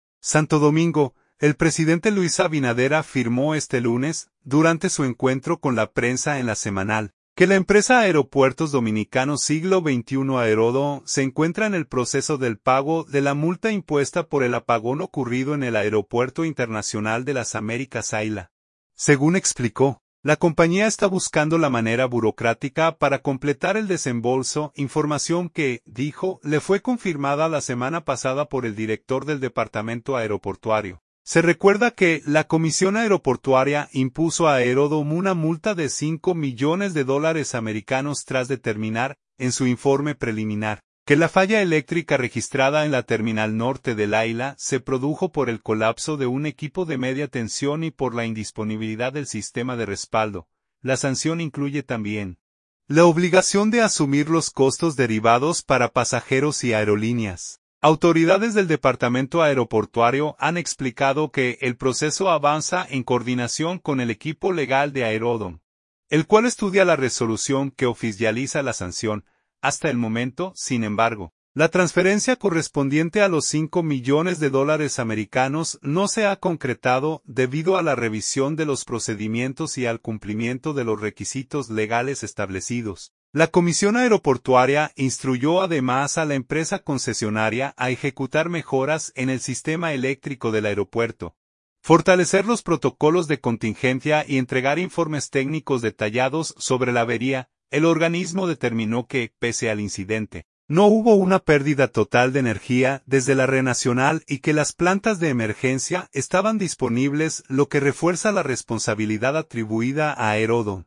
Santo Domingo.– El presidente Luis Abinader afirmó este lunes, durante su encuentro con la prensa en “La Semanal”, que la empresa Aeropuertos Dominicanos Siglo XXI (Aerodom) se encuentra “en el proceso del pago” de la multa impuesta por el apagón ocurrido en el Aeropuerto Internacional de Las Américas (AILA).